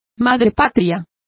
Complete with pronunciation of the translation of motherlands.